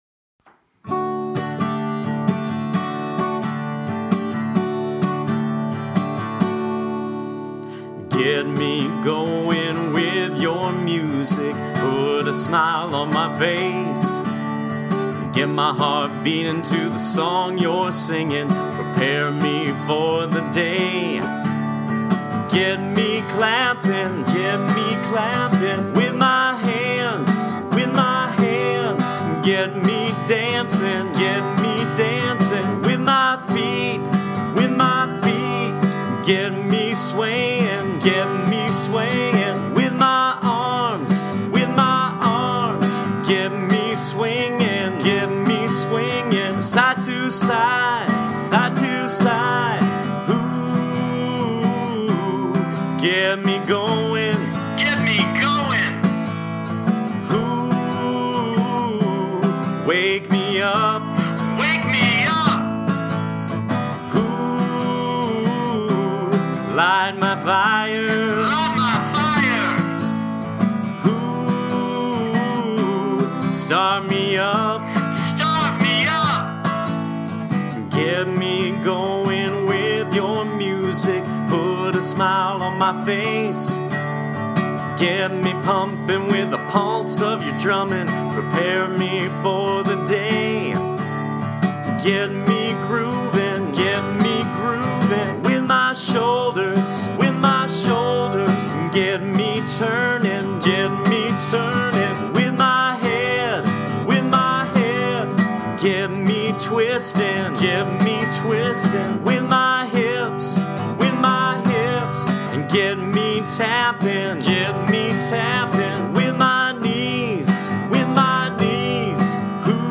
For the last several years I have always taken the time in September to write a new opening/greeting song.
This time I started the song in G Mixolydian, and later added moved it to D Ionian.
This song includes different body parts to get moving and offers sections for echo singing or singing on “oo”.